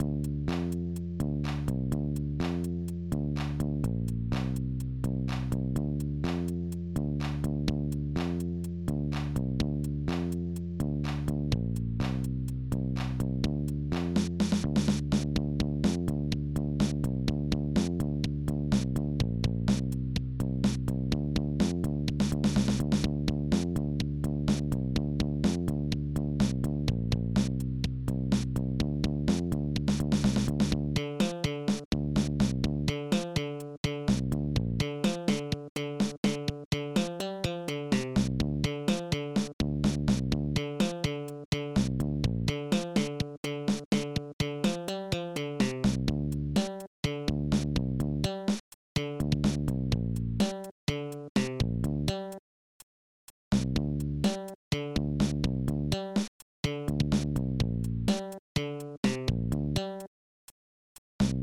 Protracker Module
Instruments elecbass filckbass s2 s4 s5 petclap